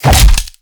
PUNCH_INTENSE_HEAVY_03.wav